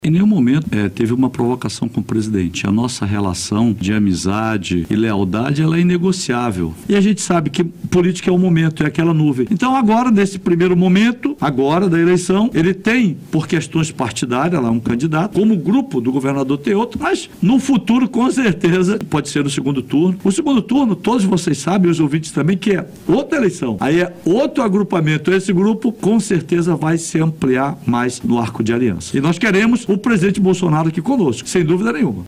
Em entrevista no BandNews Amazônia 1ª Edição nesta terça-feira, 06, Menezes negou que tenha provocado o ex-presidente. Ele afirmou ainda que, em caso de segundo turno, conta com apoio de Bolsonaro: (Ouça)